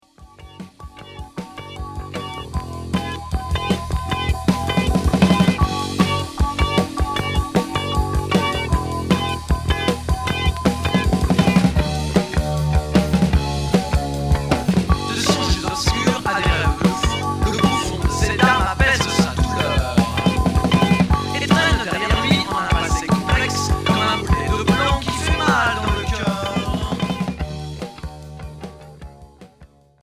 Progressif Unique 45t retour à l'accueil